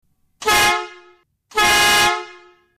Horn Big Blast III
dB 115
Big_Blast_III.mp3